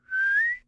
描述：滑稽的哨声
标签： 滑稽 soud效果 口哨
声道立体声